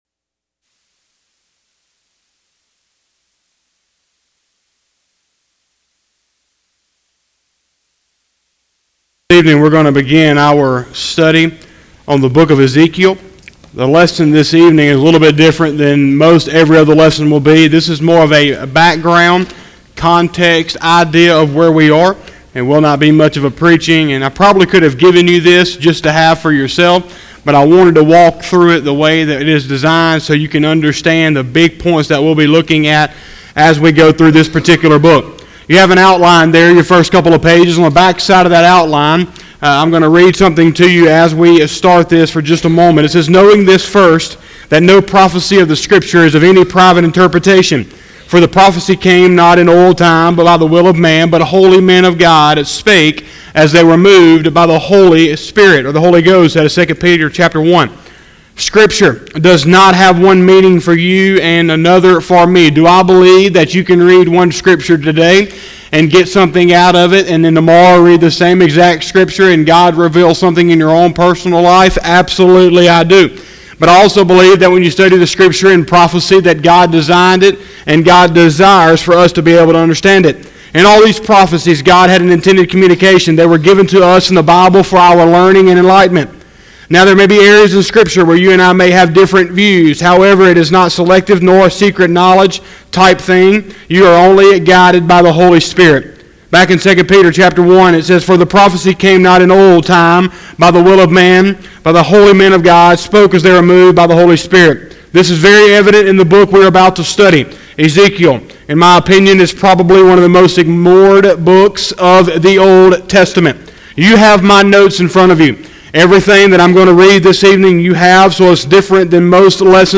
02/02/2020 – Sunday Evening Service